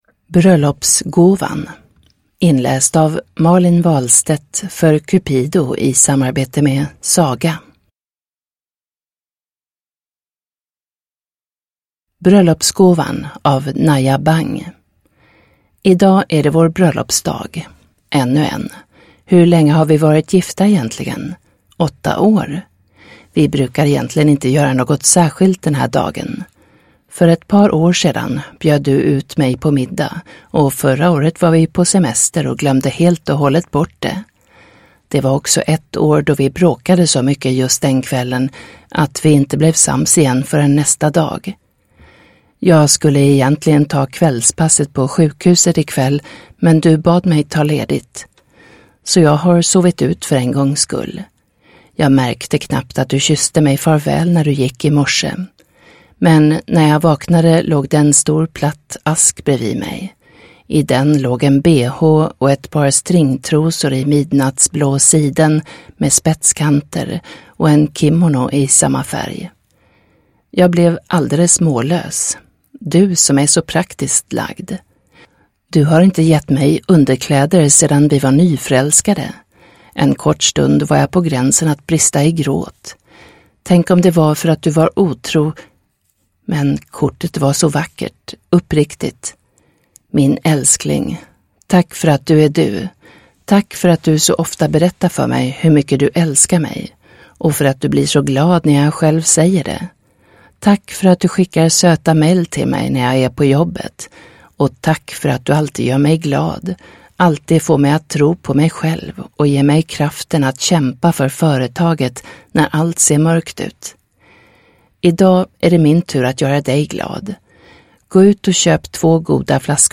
Bröllopsgåvan (ljudbok) av Cupido